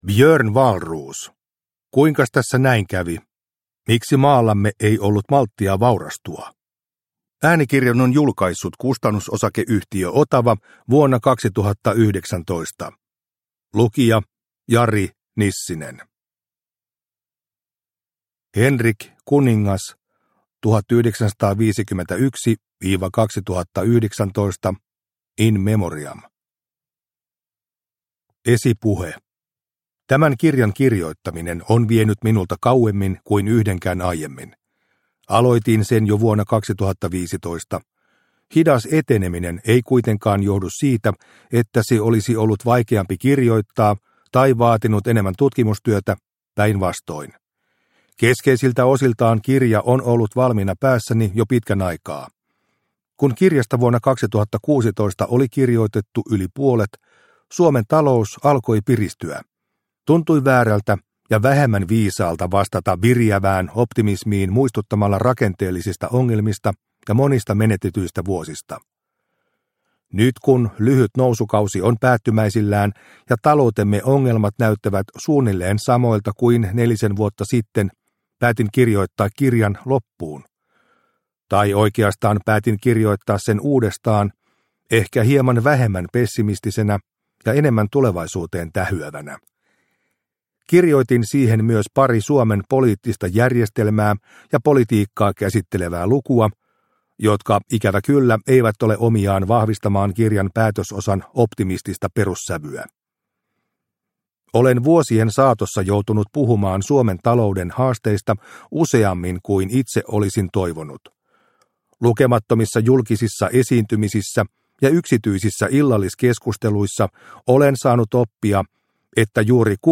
Kuinkas tässä näin kävi? – Ljudbok – Laddas ner